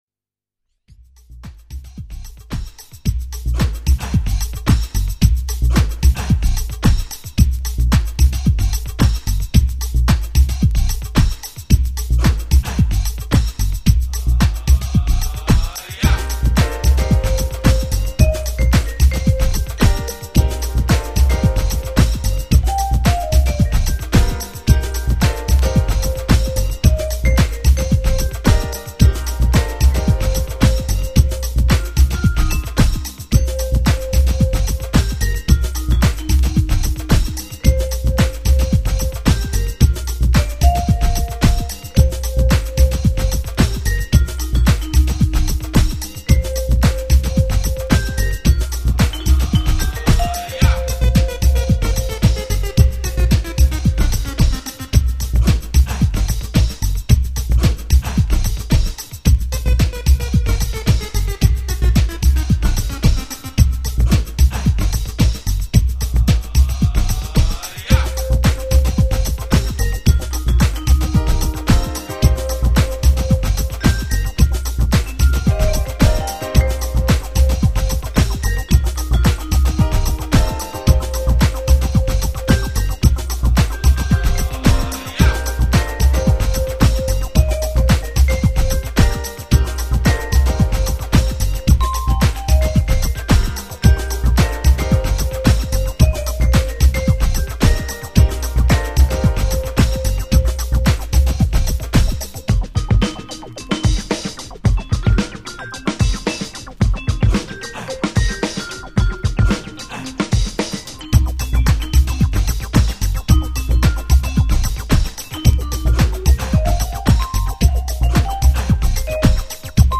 ACID-JAZZ